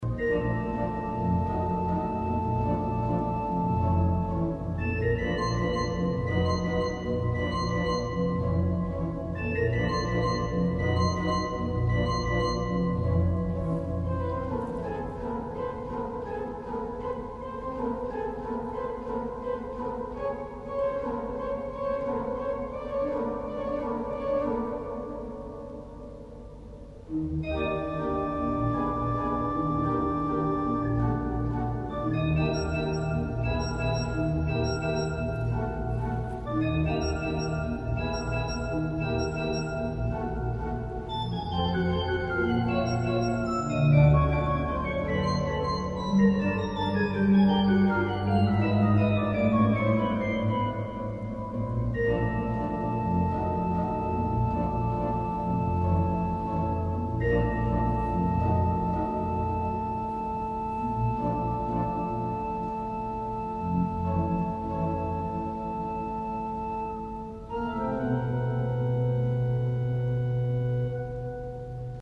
à l'orgue de La Madeleine (Paris)